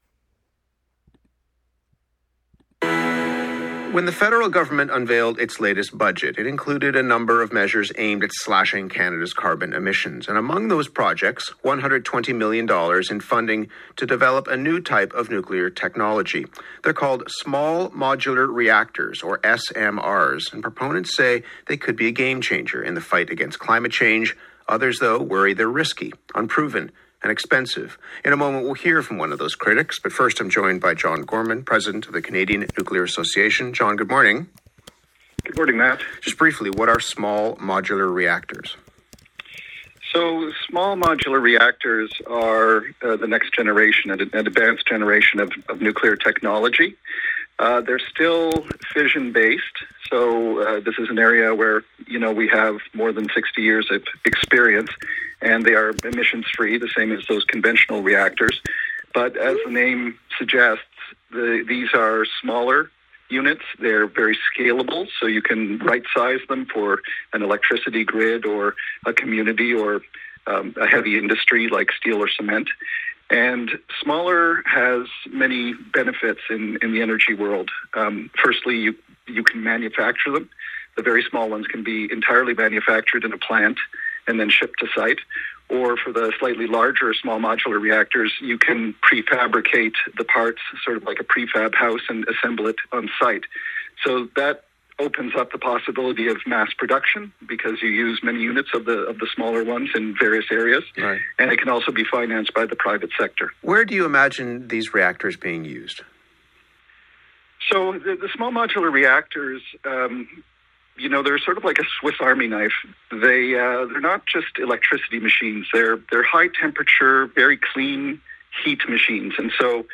SMRs debated on CBC’s The Current